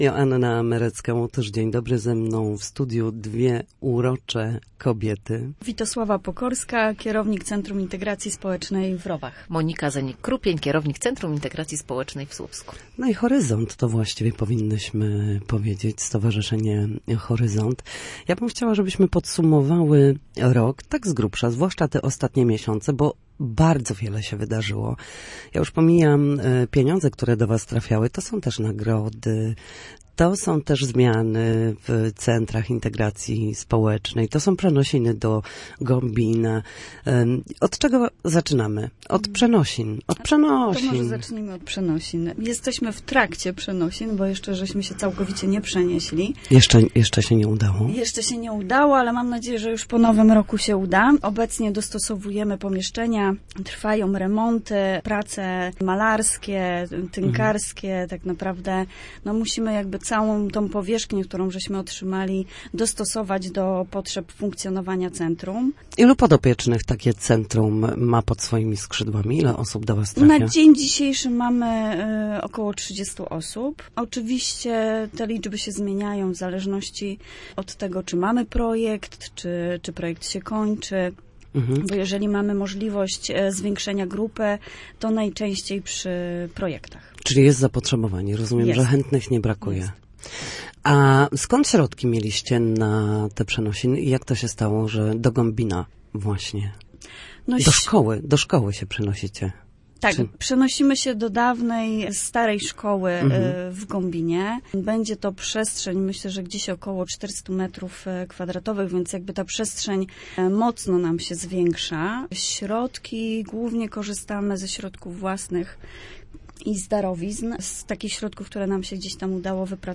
Na naszej antenie podsumowały mijający rok.